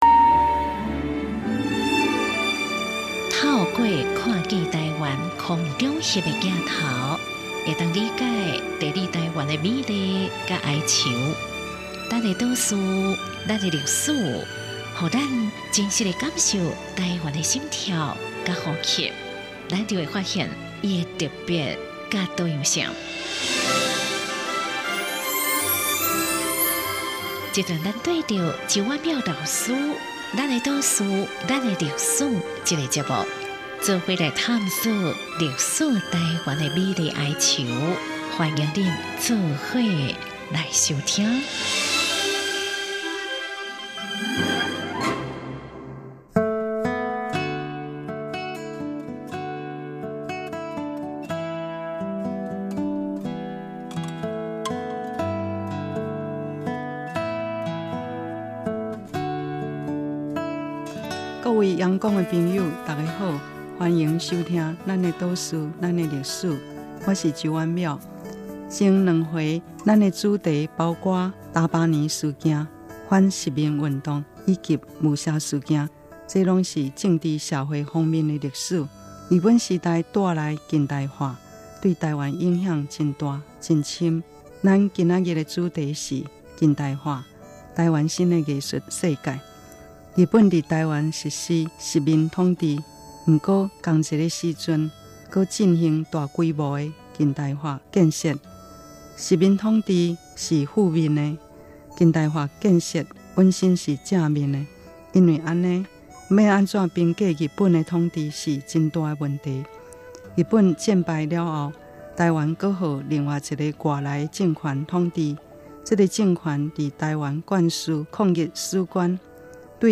最後，我們朗讀高一生的〈春之佐保姬〉的歌詞以緬懷故人。